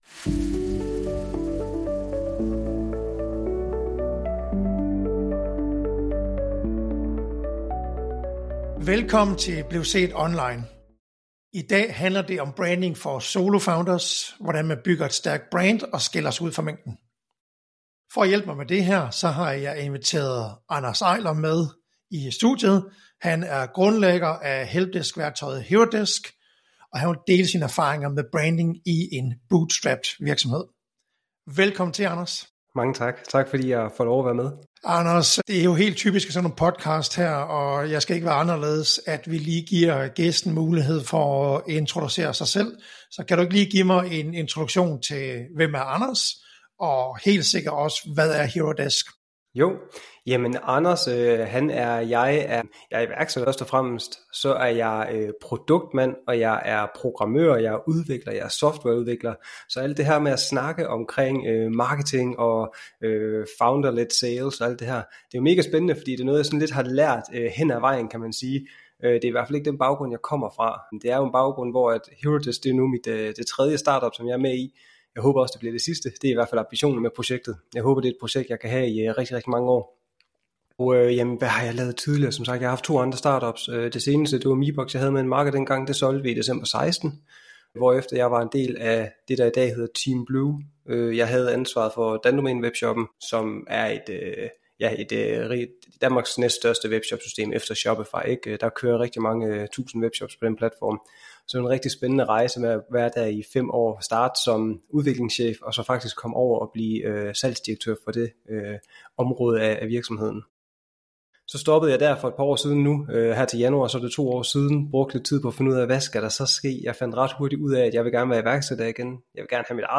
Vi diskuterer, hvordan virksomheder kan maksimere deres digitale synlighed med minimal indsats og maksimalt resultat. I hver episode inviterer vi eksperter og thought leaders til uformelle samtaler, hvor de deler deres perspektiver og erfaringer inden for SEO og online markedsføring.